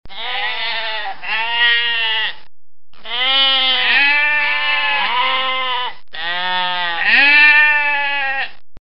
0:00 Group: Tiere ( 625 241 ) Rate this post Download Here!